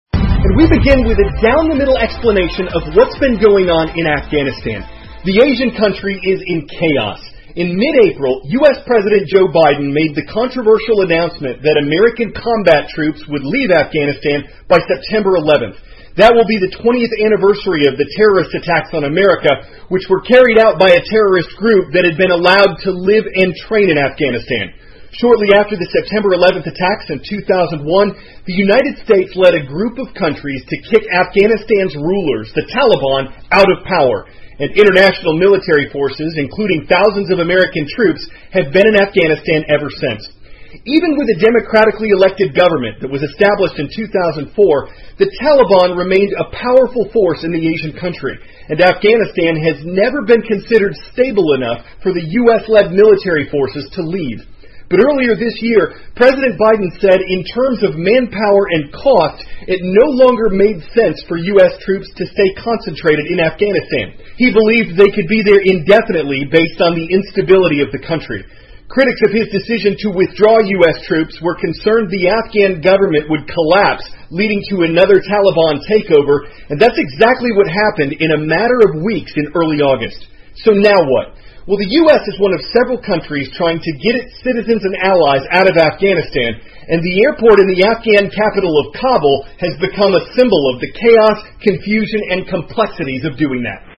美国有线新闻 CNN 美军撤离阿富汗引混乱 听力文件下载—在线英语听力室